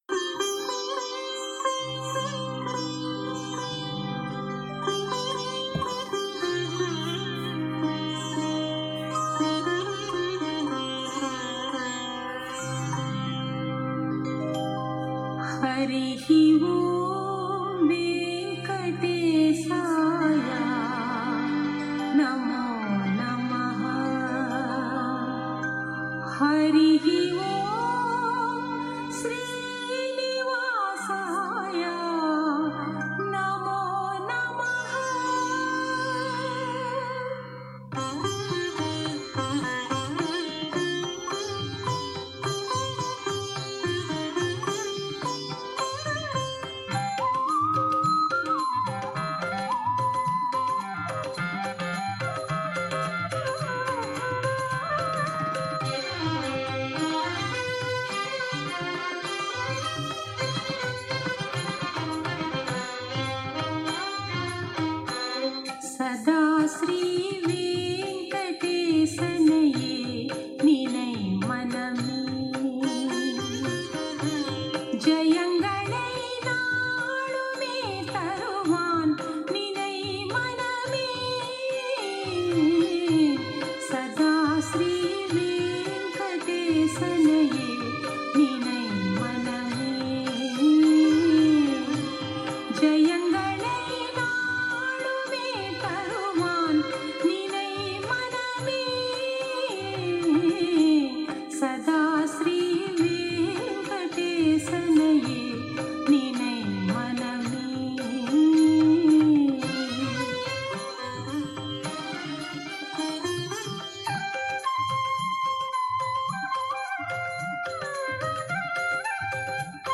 மதுரகவி ஆழ்வாரை போலவே அடியேனும் 12 ஆழ்வார்களின் சில பாசுரங்களை சங்கீர்த்தனம் மூலம் பாட விரும்புகிறேன்.